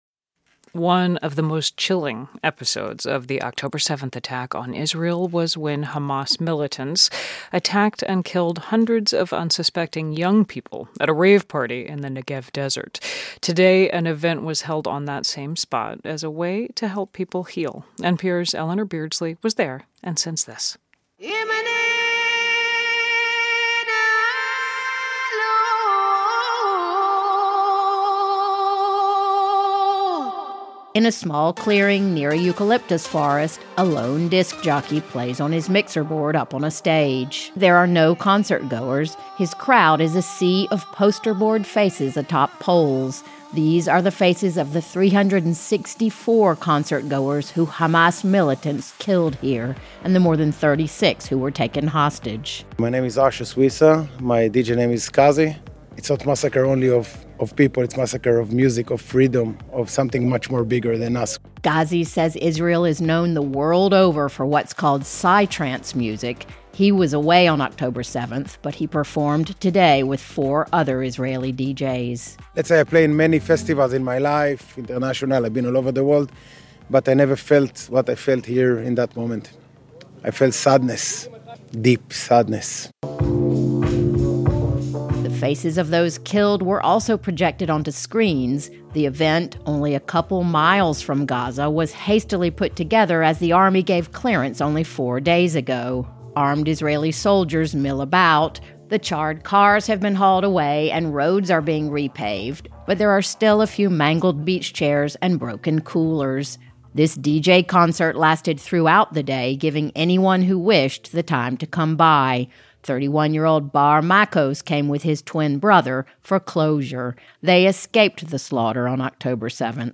At Israeli rave site attacked by Hamas, DJs play music to honor the dead and missing